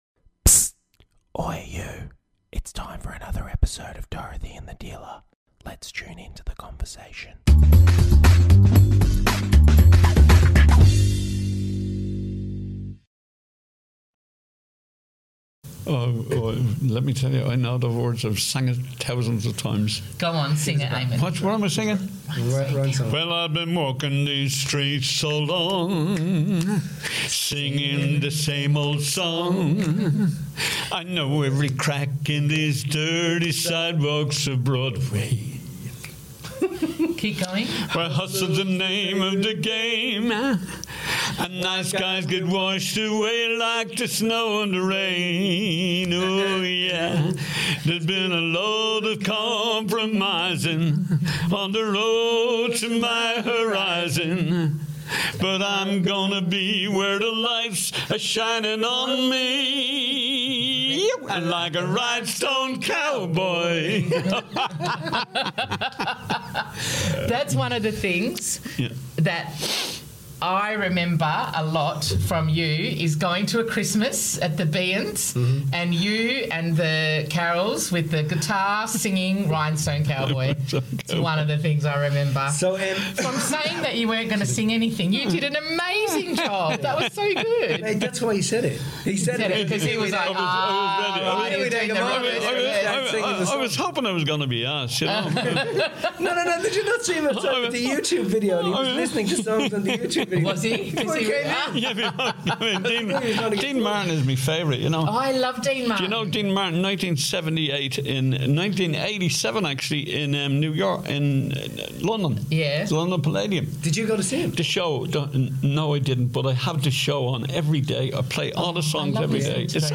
Join us for a fun, lighthearted conversation that proves behind every great coach is an even greater dad.